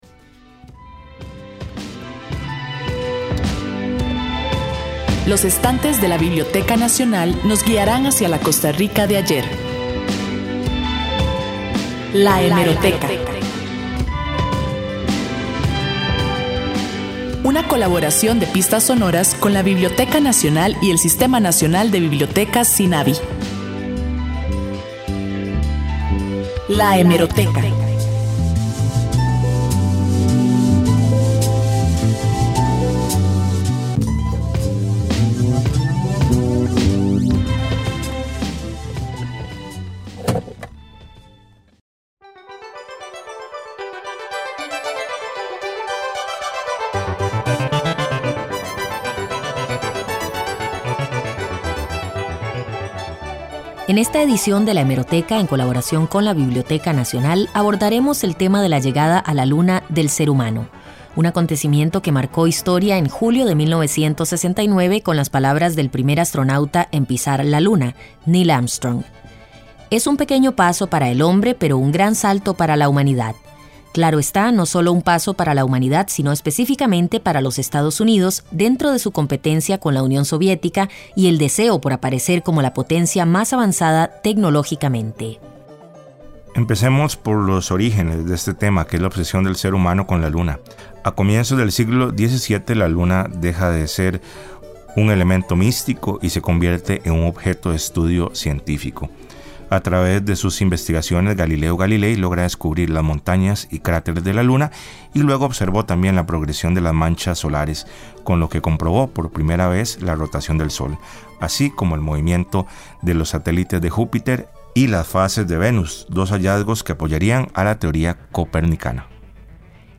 Sección de la Biblioteca Nacional en el Programa Pistas Sonoras de Radio Universidad, transmitido el 6 de agosto del 2022. Este espacio es una coproducción de las Radios de la Universidad de Costa Rica y la Biblioteca Nacional con el propósito de difundir la cultura costarricense.